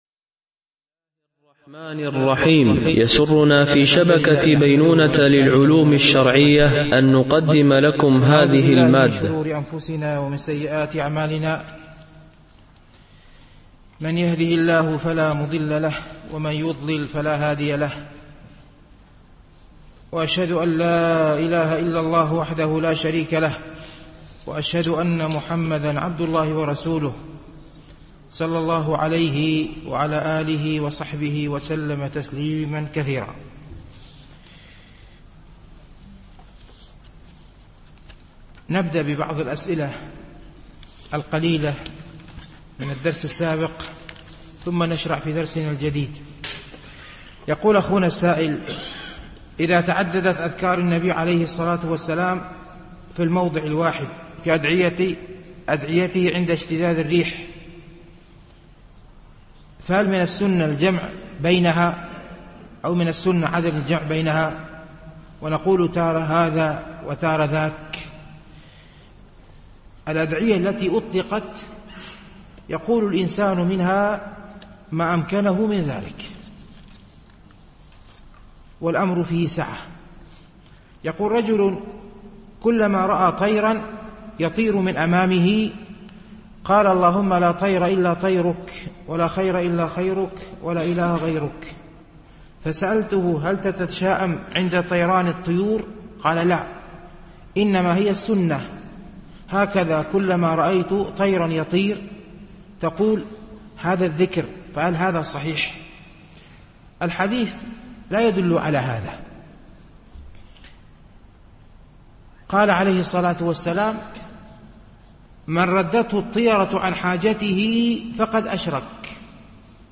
شرح رياض الصالحين – الدرس 225 ( الحديث 842 – 843 )